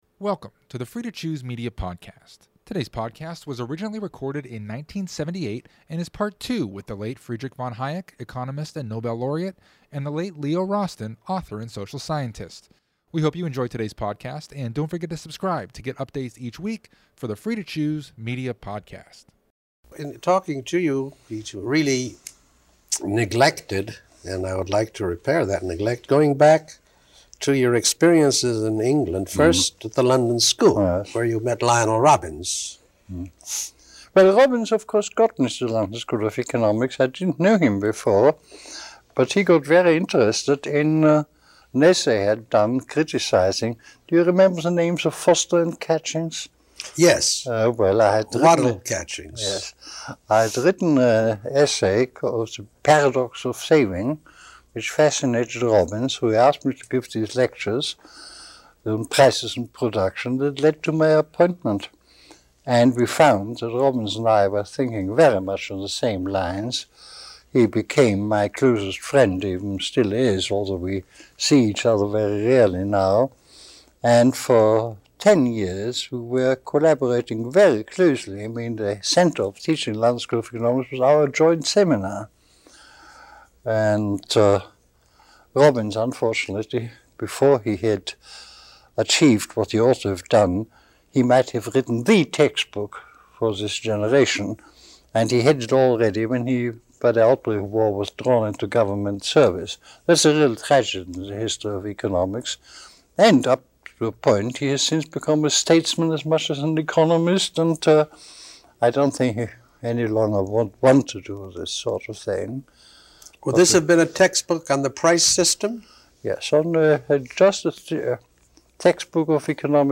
This is the second hour between the late Friedrich von Hayek, economist and Nobel laureate, and the late Leo Rosten, author and social scientist.